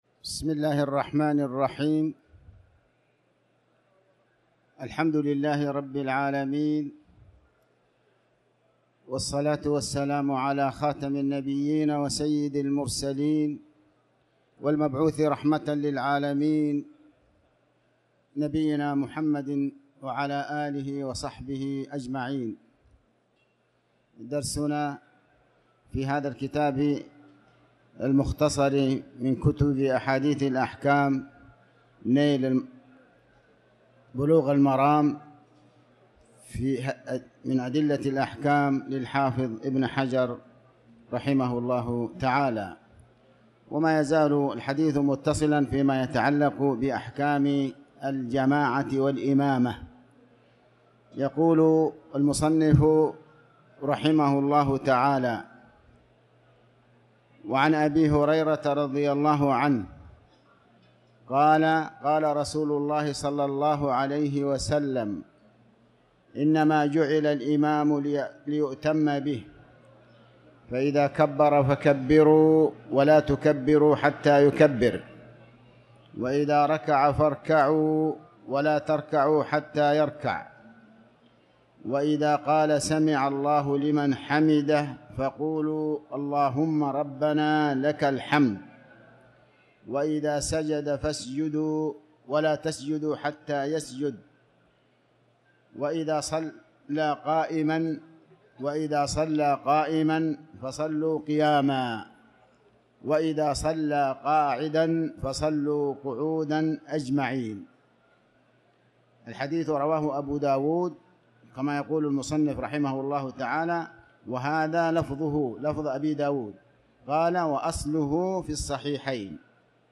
تاريخ النشر ٢١ رجب ١٤٤٠ هـ المكان: المسجد الحرام الشيخ